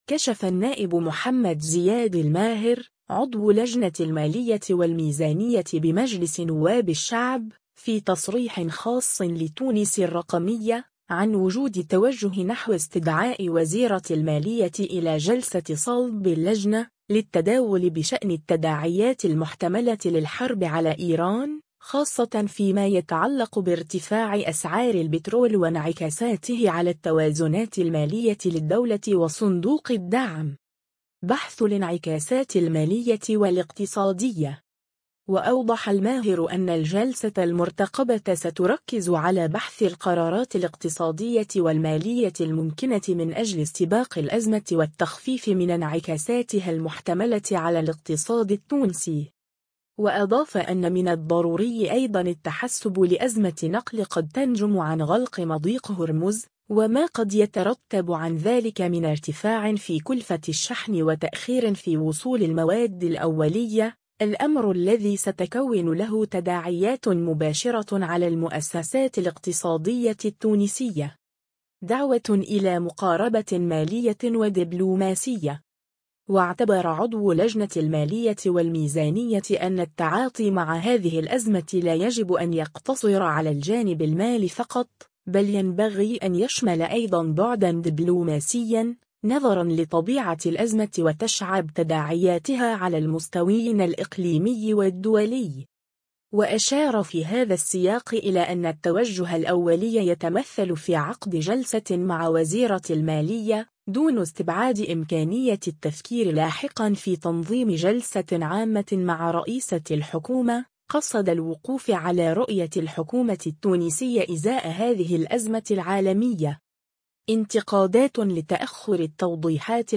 كشف النائب محمد زياد الماهر، عضو لجنة المالية والميزانية بمجلس نواب الشعب، في تصريح خاص لـ”تونس الرقمية”، عن وجود توجه نحو استدعاء وزيرة المالية إلى جلسة صلب اللجنة، للتداول بشأن التداعيات المحتملة للحرب على إيران، خاصة في ما يتعلق بارتفاع أسعار البترول وانعكاساته على التوازنات المالية للدولة وصندوق الدعم.